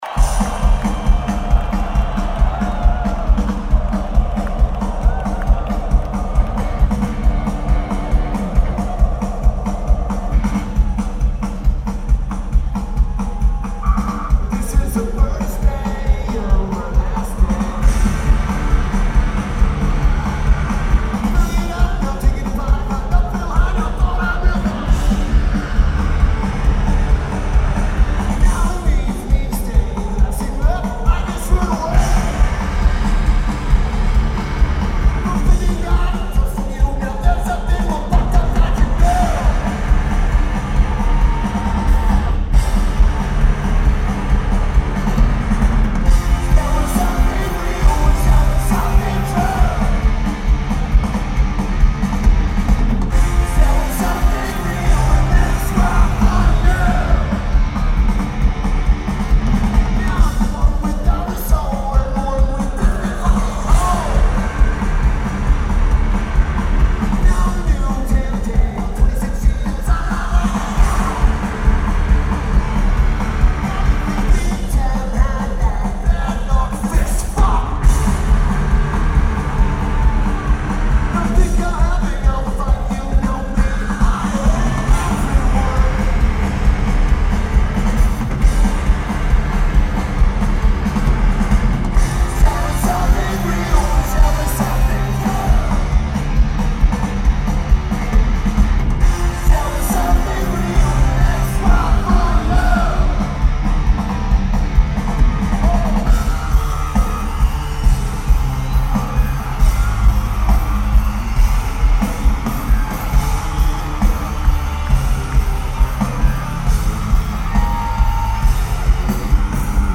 1st Bank Center
Drums
Backing Vocals
Bass
Guitar